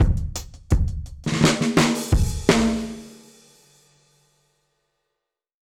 Index of /musicradar/dub-drums-samples/85bpm
Db_DrumsB_Dry_85-04.wav